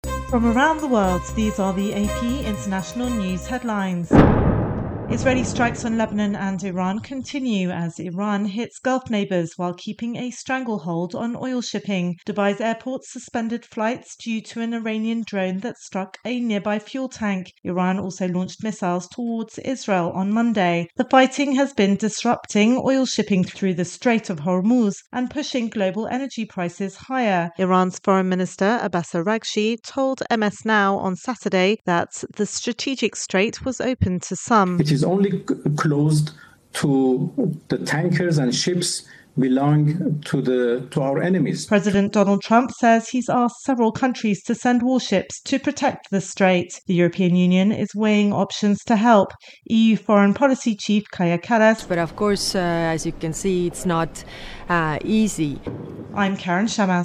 AP correspondent